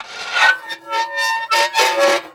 grind.ogg